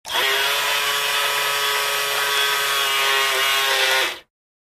fo_toy_motor_01_hpx
Small toy motor spins at variable speeds. Motor, Toy Buzz, Motor